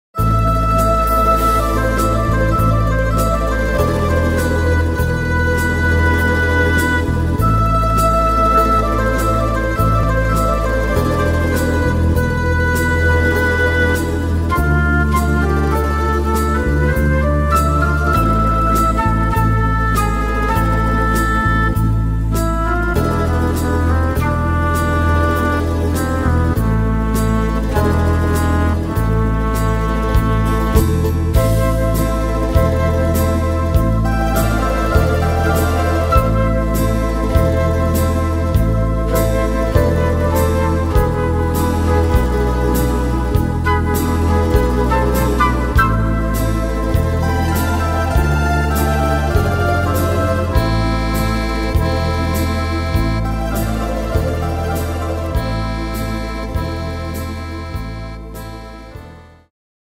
Tempo: 100 / Tonart: A-moll